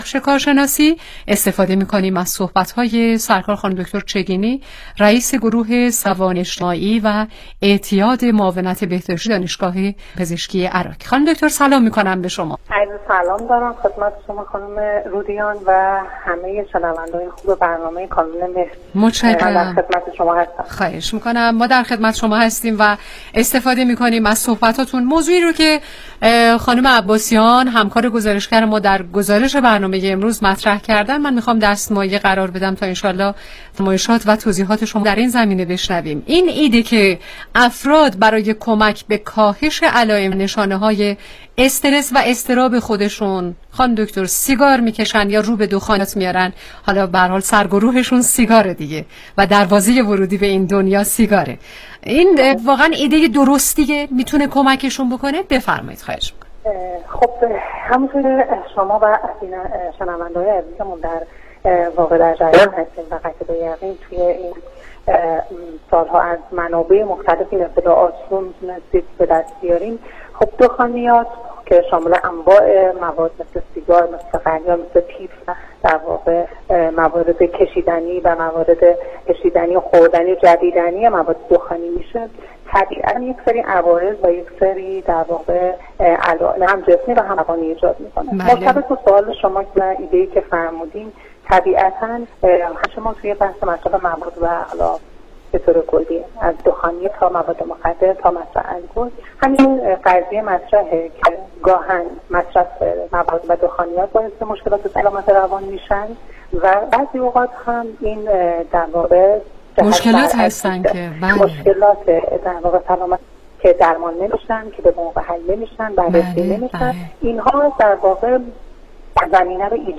برنامه رادیویی